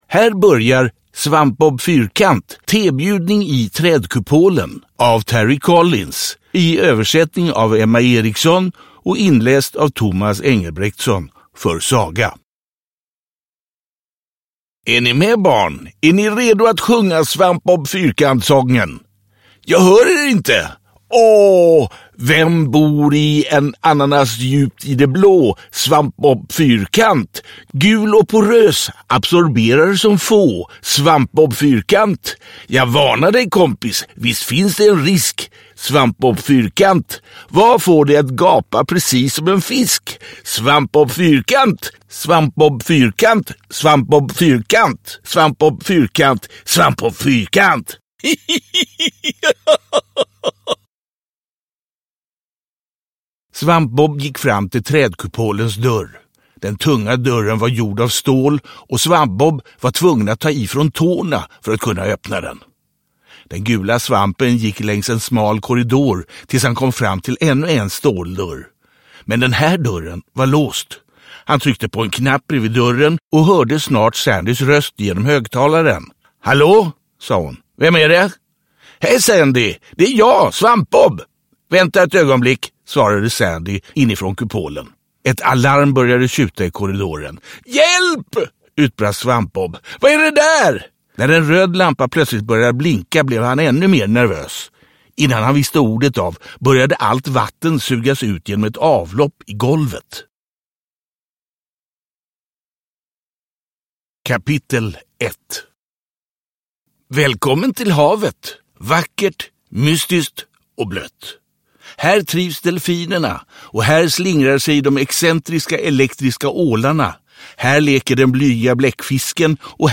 SvampBob Fyrkant – Tebjudning i trädkupolen – Ljudbok